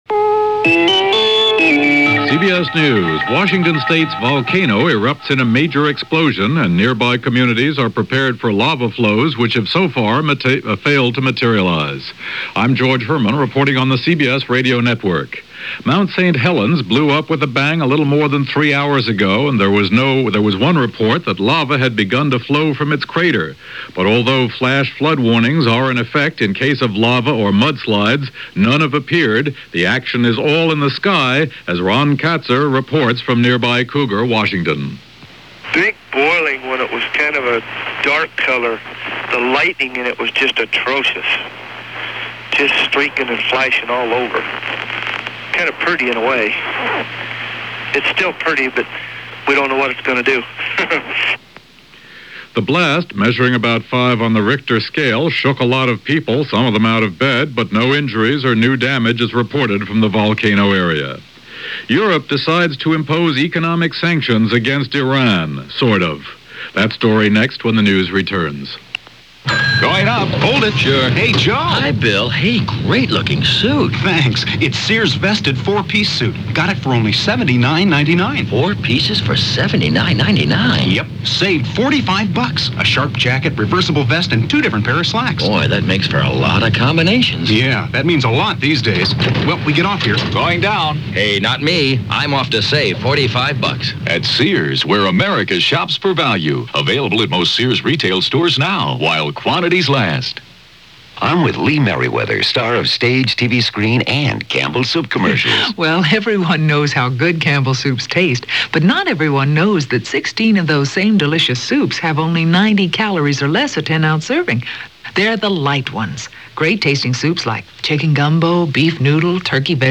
All that, and much-much more for this May 18, 1980 as reported by George Herman and the CBS Hourly News.